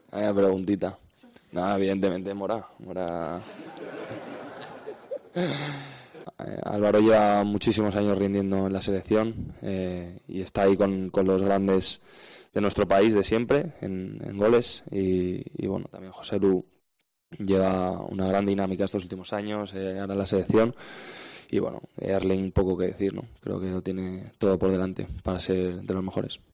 El centrocampista español acudió a un acto publicitario en la mañana de este martes y vivió un momento incómodo cuando fue preguntado por su compañero en el City y por Morata.